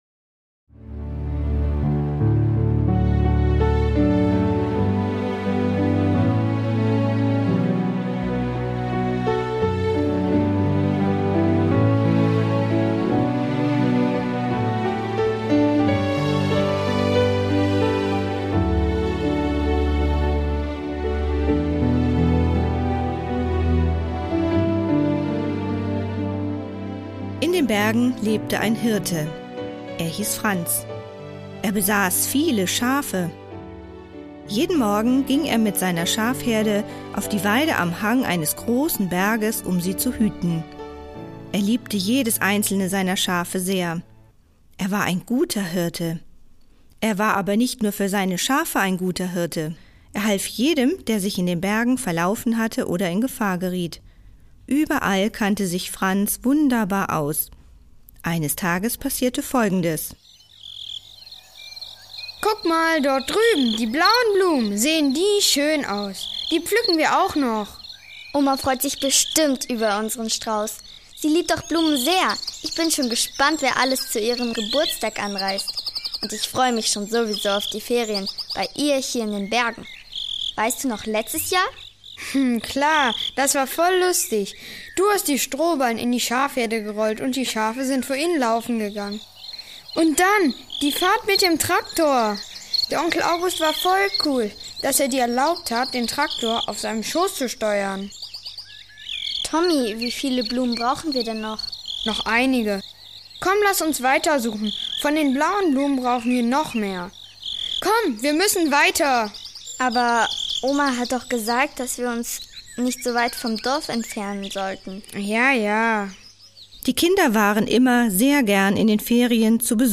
Etwas für die Jüngsten und Junggebliebenen: Ein Stück der Puppenbühne Senfkörnchen: Der gute Hirte - Tommy und Lena geraten in große Gefahr... Doch der gute Hirte ist schon unterwegs!